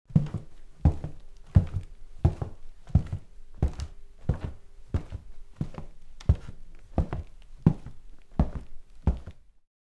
Звуки шагов, бега
На этой странице собраны разнообразные звуки шагов и бега человека по различным покрытиям: от звонких шагов по паркету до приглушенных звуков движения по ковру.
Шаги по деревянному полу